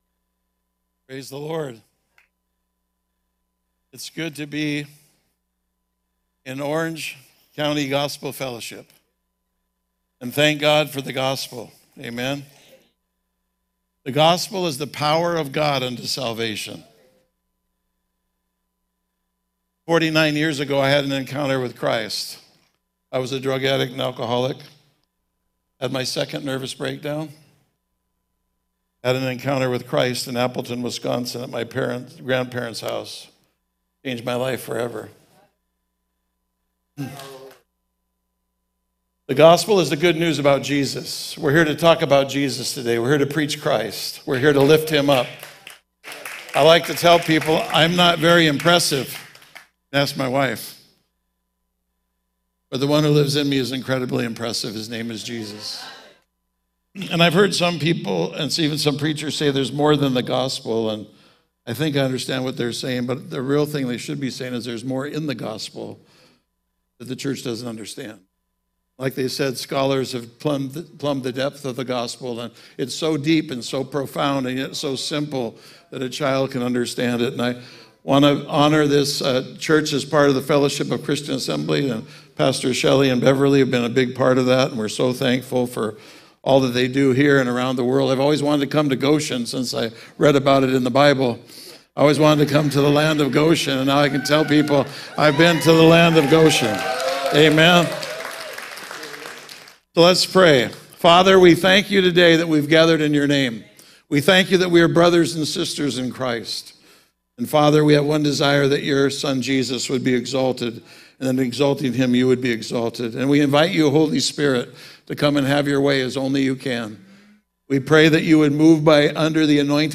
Sermons - Celebration OC Gospel Fellowship